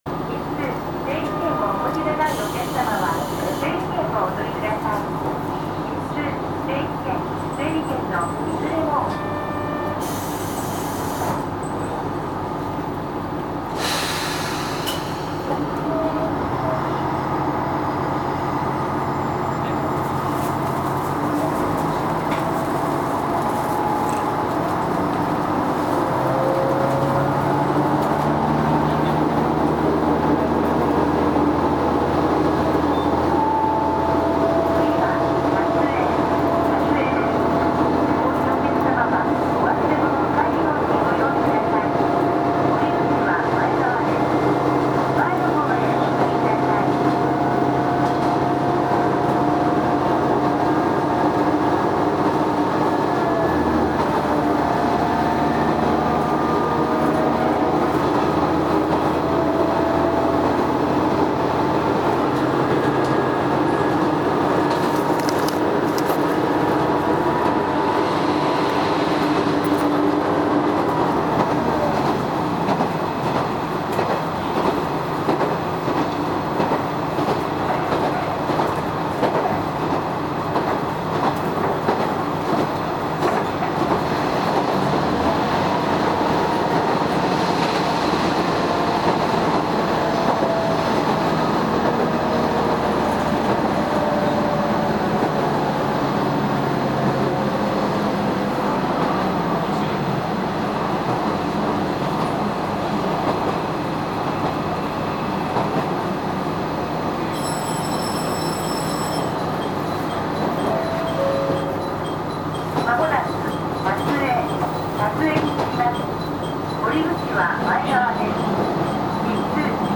走行音
録音区間：阿波赤石～立江(お持ち帰り)